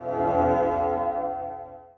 XBOX Three Startup.wav